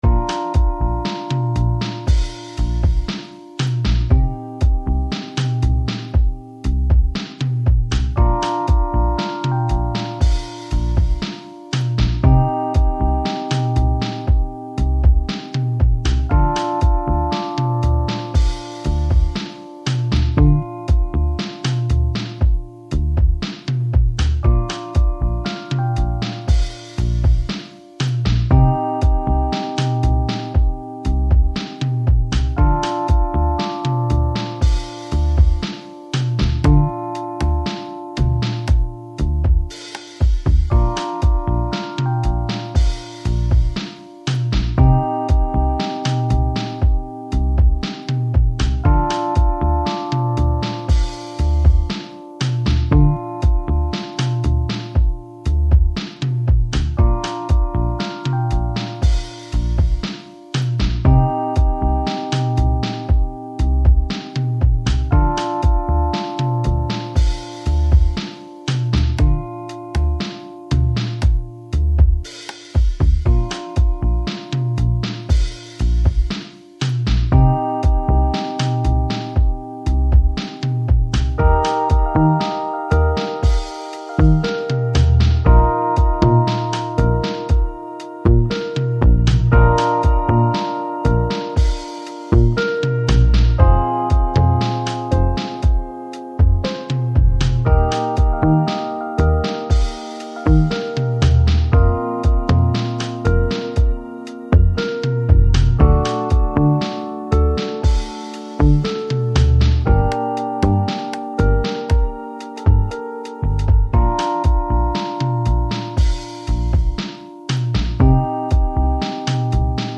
Жанр: Lounge, Chill Out, Downtempo, Ambient